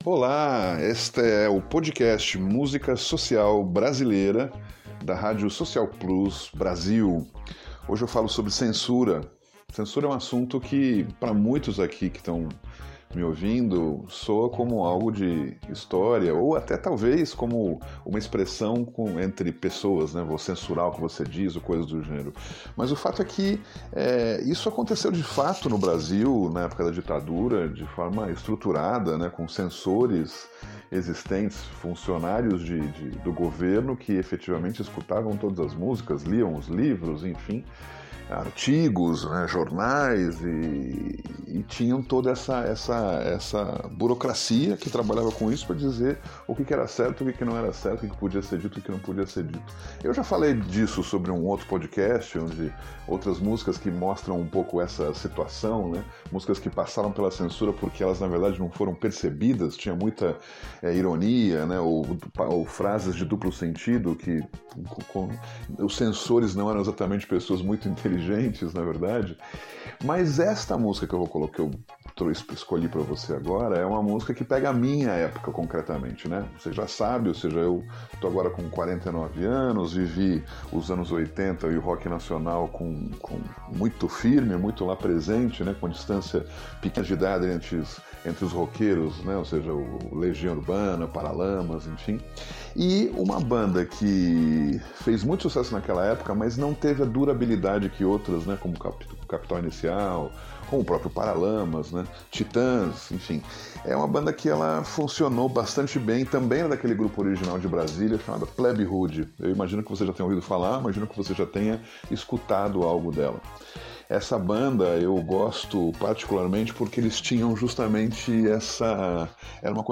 Foi uma época onde valia a pena ainda reclamar do que estava muito recente e foi isso que fizeram nesta música pós-punk.